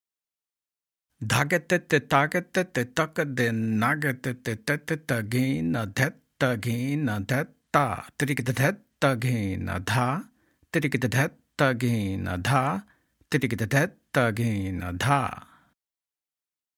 Demonstrations
Spoken